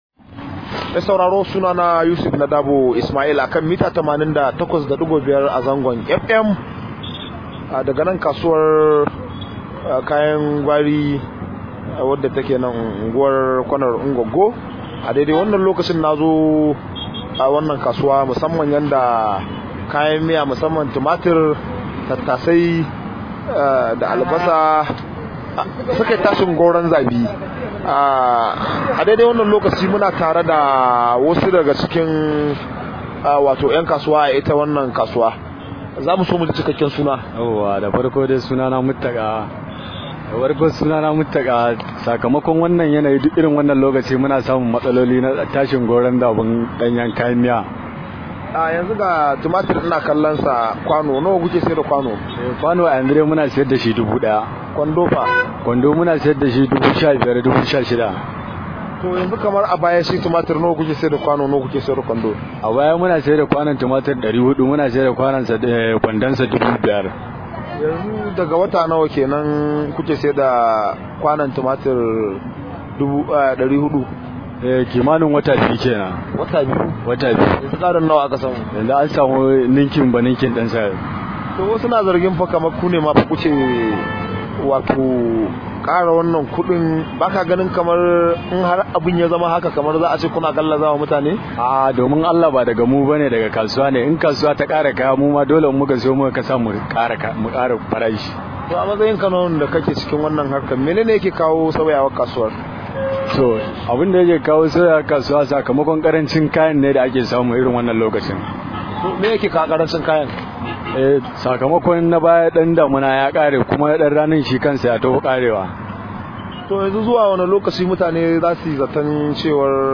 Rahoto: Sai Damina ta yi nisa za a samu sauƙin tsadar kayan miya – Mai kayan Gwari